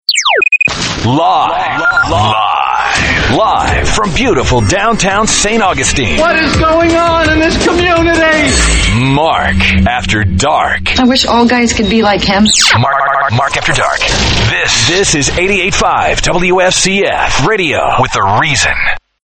RADIO IMAGING